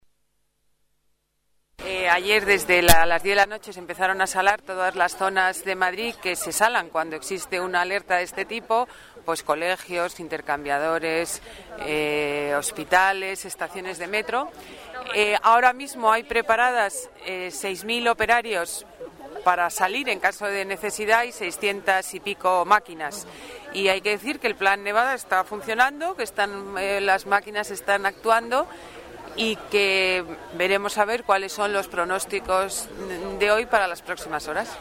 Nueva ventana:Declaraciones de la delegada de Medio Ambiente, Ana Botella